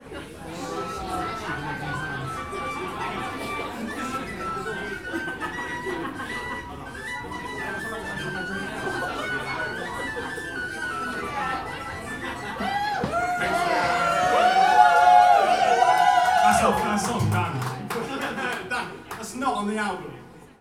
Bootleg media